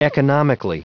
Prononciation du mot economically en anglais (fichier audio)
economically.wav